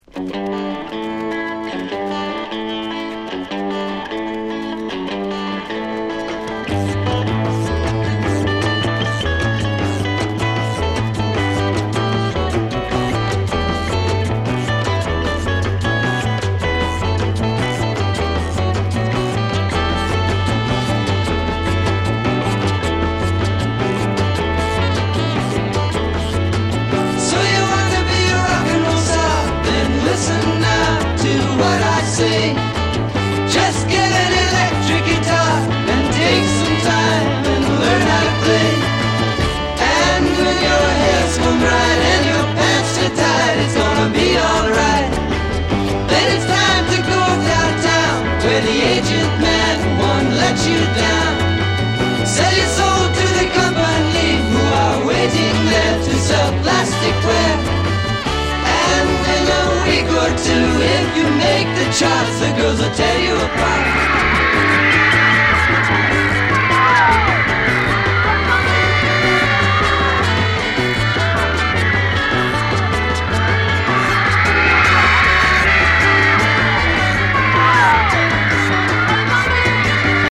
西海岸フォークロックバンド